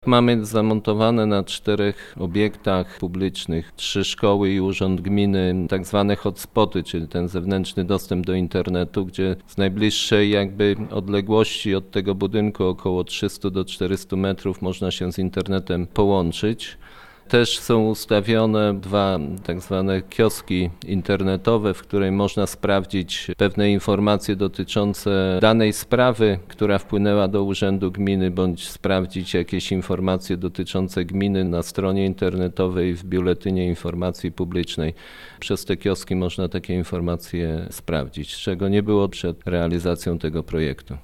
Jacek Anasiewicz podkreśla, że e-urząd to nie tylko informatyzacja urzędu, to także większy dostęp mieszkańców do internetu: